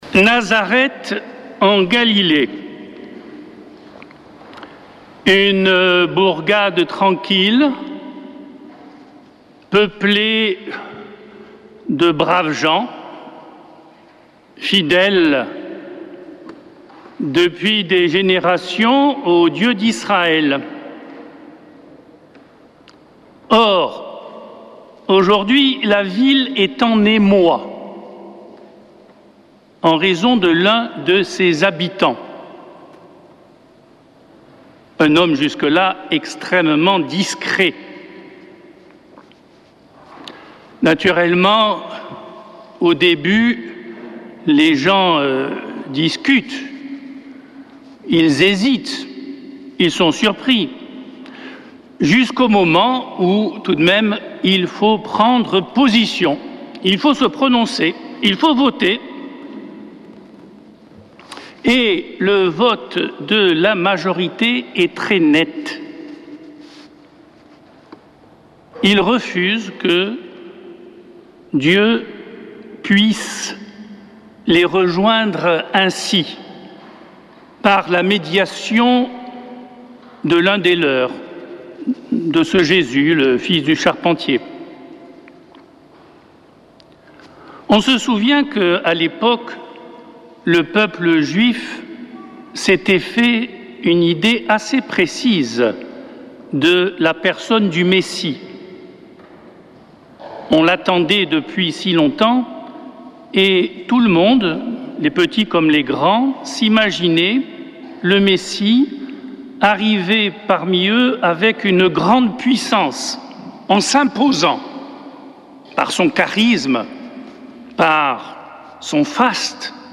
Homélie du 7 juillet